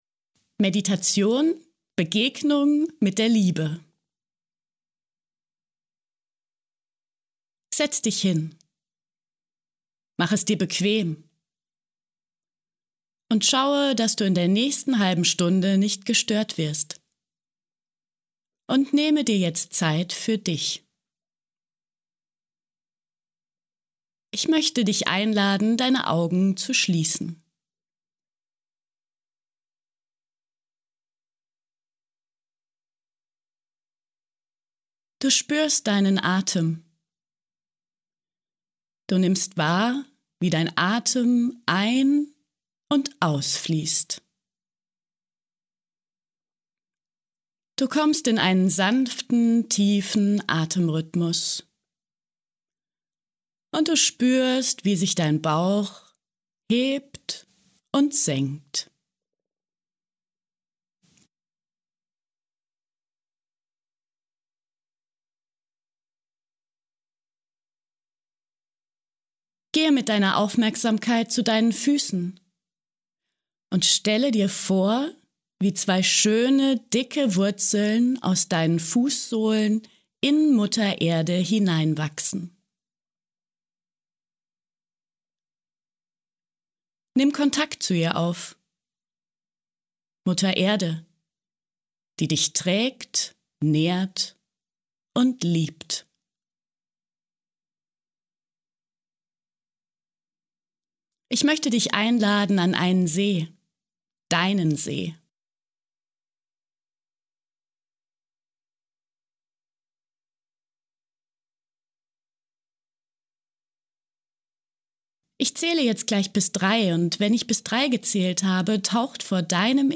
Hier kannst du dir die Meditation „Begegnung mit der Liebe“ herunterladen oder gleich im Browser anhören: